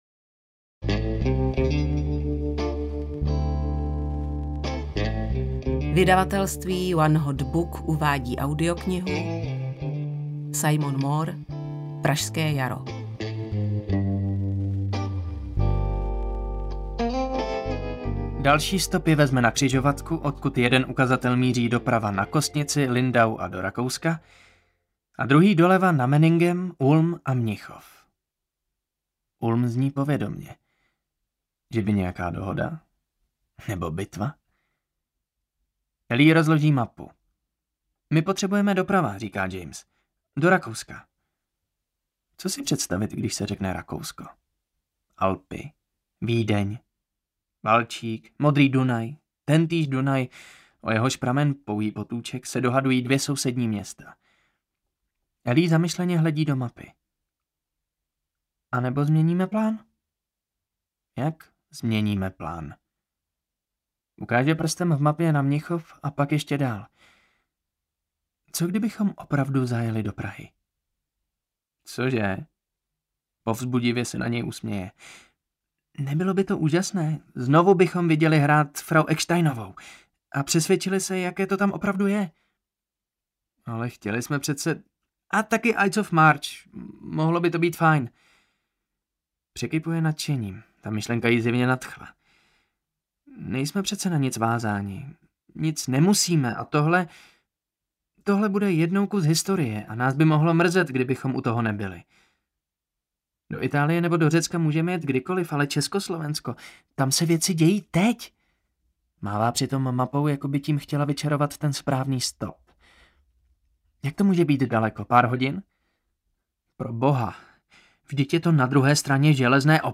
Pražské jaro audiokniha
Ukázka z knihy